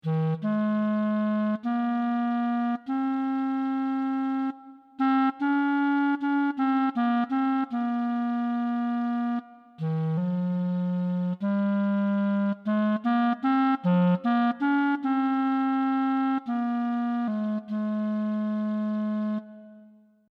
As a first approach, we have assumed that a MIDI-controlled digital clarinet synthesiser based on physical models is a sufficiently good instrument model.